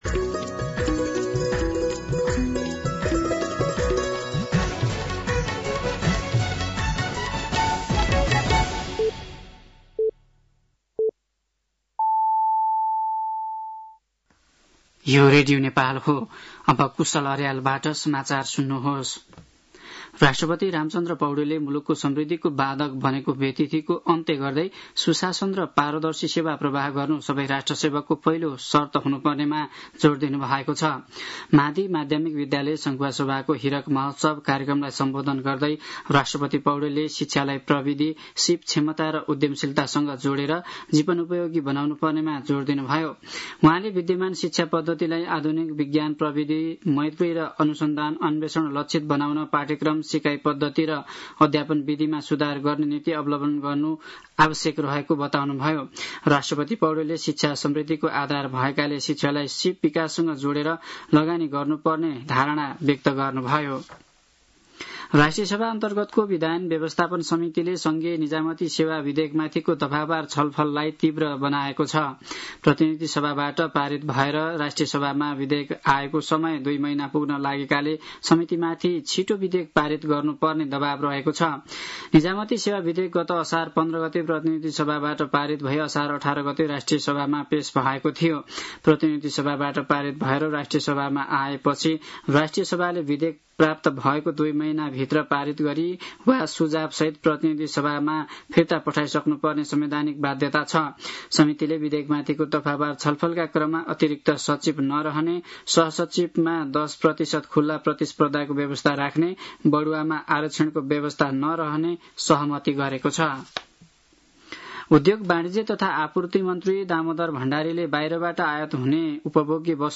साँझ ५ बजेको नेपाली समाचार : १२ भदौ , २०८२
5-pm-nepali-news-5-12.mp3